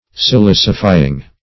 Silicify \Si*lic"i*fy\, v. t. [imp. & p. p. Silicified; p. pr.
silicifying.mp3